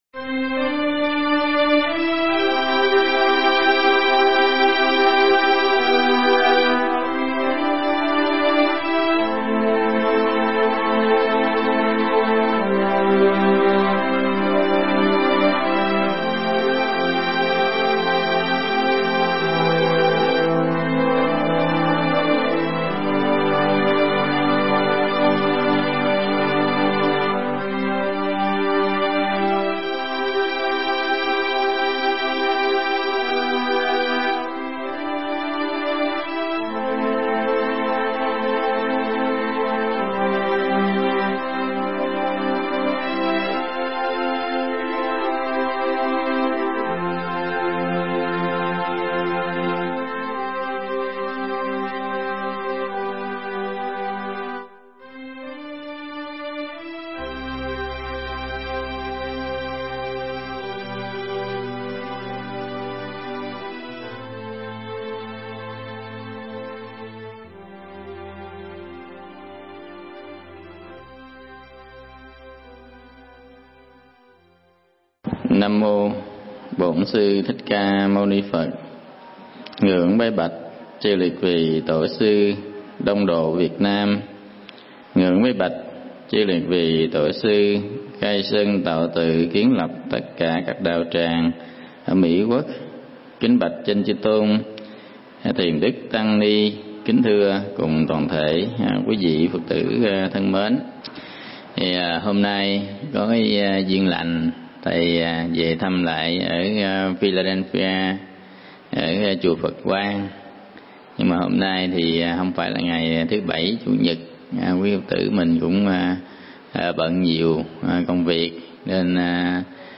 Pháp Thoại Con đường giải thoát